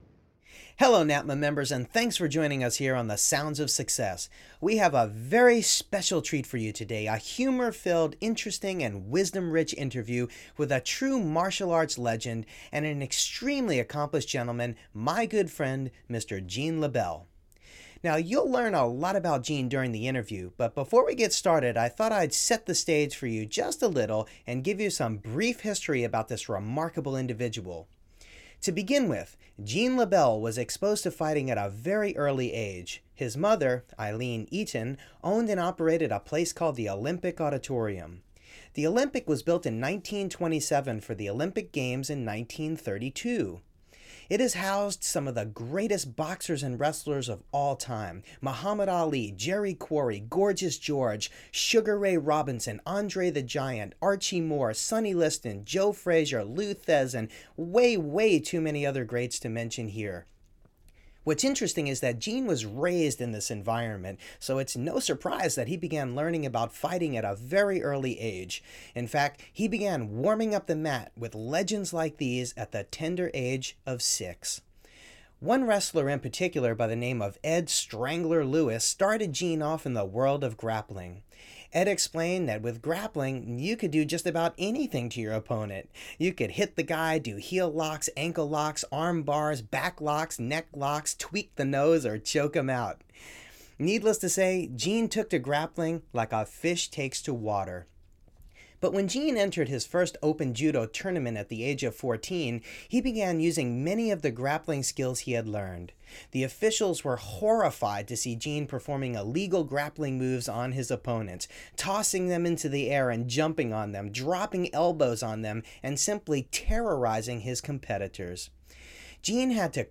Interview with Gene LeBell – The Toughest Man Alive